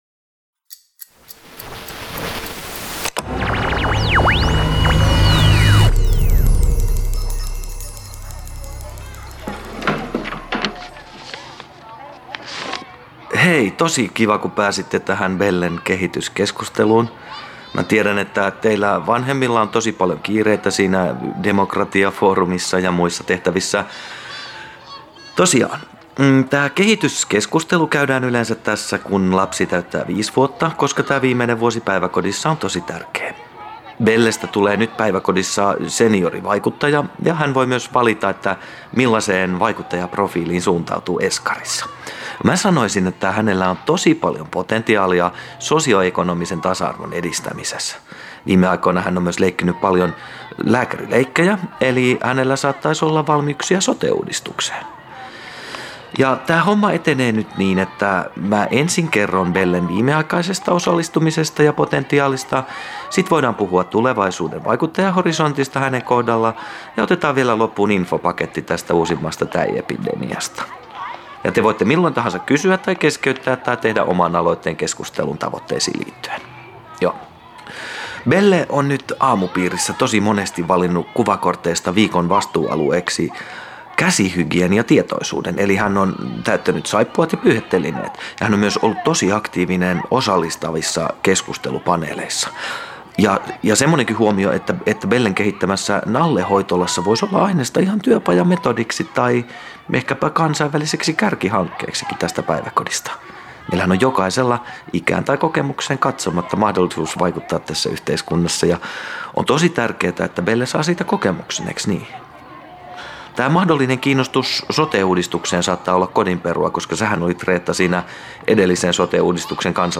Kuunnelma
4-aktiivinen-kansalainen-pk-keskustelu.mp3